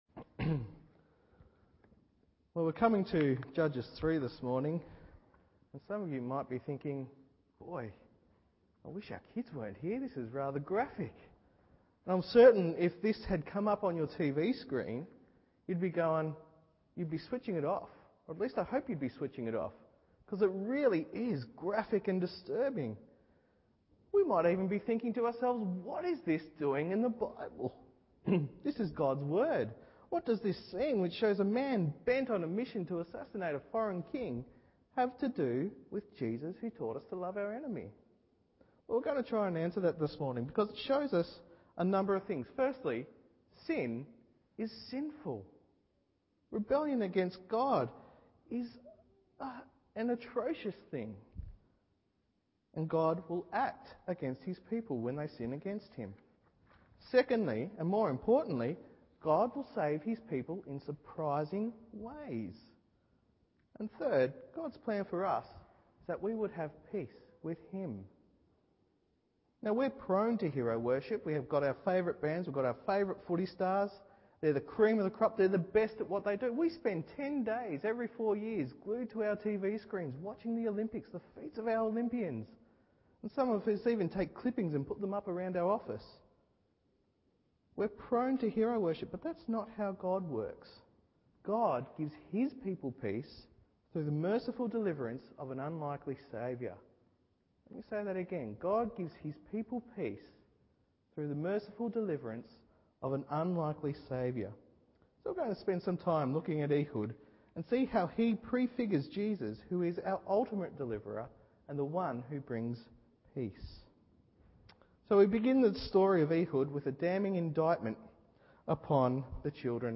Morning Service Judges 3:12-30 1. A Problem Child 2. The Unlikely Man 3.